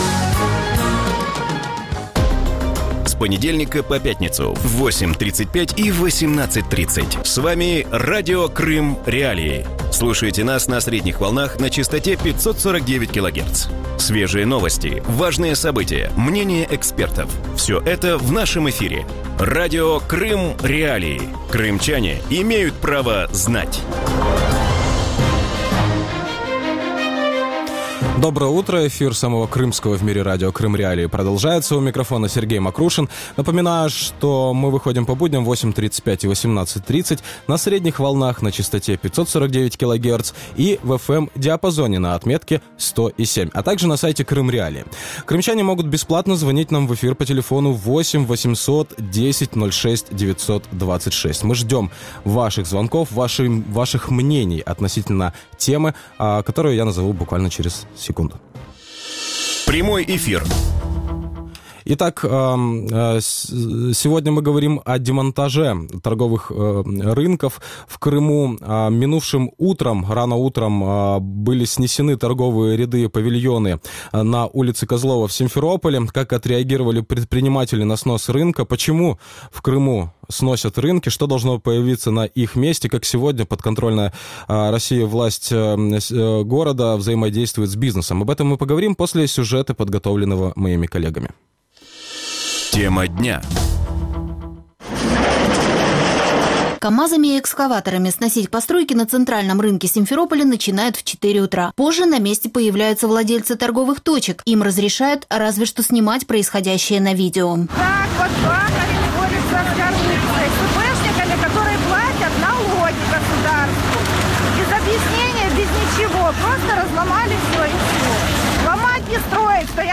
Утром в эфире Радио Крым.Реалии говорят о демонтаже рынков в Симферополе. Очередной городской рынок снесли на улице Козлова.